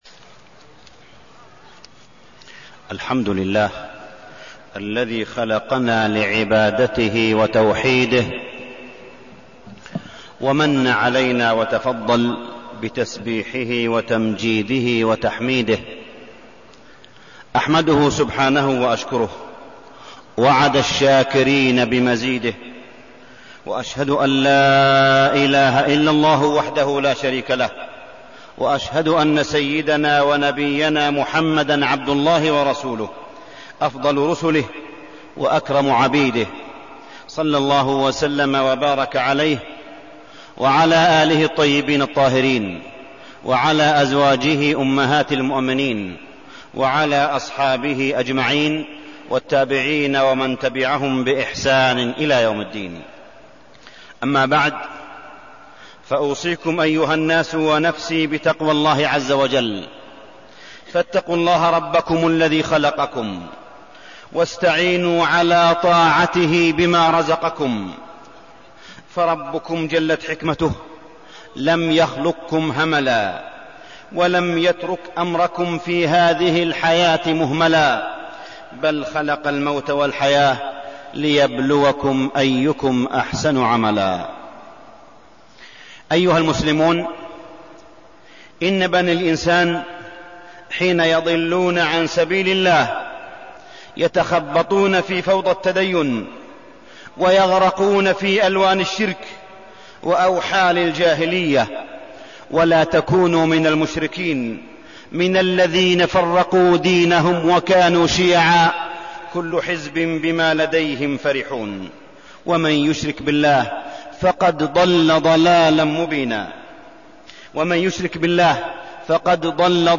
تاريخ النشر ٢ ربيع الثاني ١٤١٧ هـ المكان: المسجد الحرام الشيخ: معالي الشيخ أ.د. صالح بن عبدالله بن حميد معالي الشيخ أ.د. صالح بن عبدالله بن حميد تحقيق العبودية لله